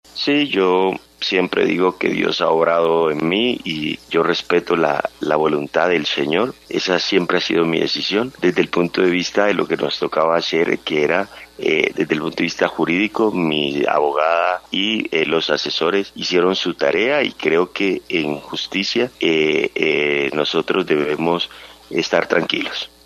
En diálogo con Caracol Radio Escobar dijo “yo siempre digo que Dios ha obrado en mí y yo respeto la voluntad del Señor, esa siempre ha sido mi decisión, desde el punto de vista jurídico, mi abogada y los asesores hicieron su tarea y creo que en justicia nosotros debemos estar tranquilos”.